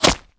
bullet_impact_rock_04.wav